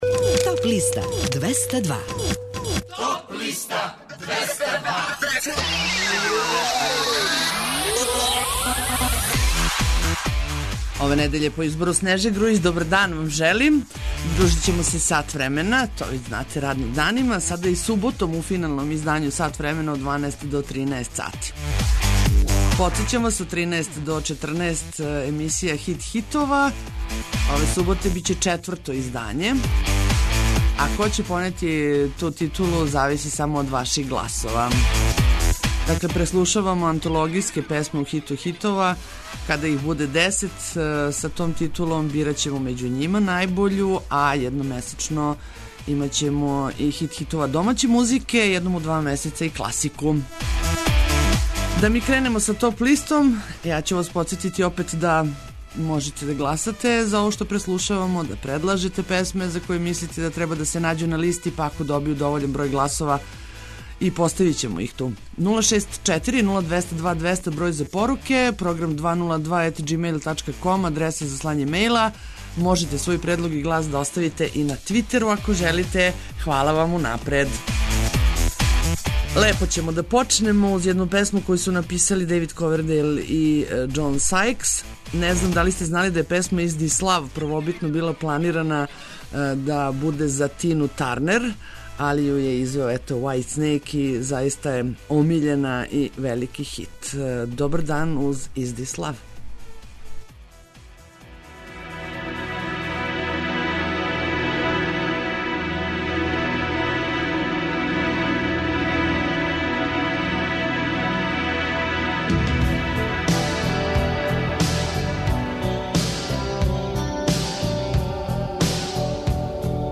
Чујте и композиције које су се нашле на подлисти лектира, класика, етно, филмска музика...